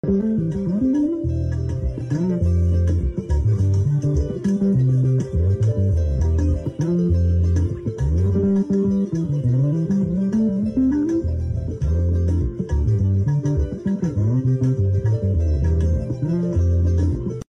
music band caver bass guitar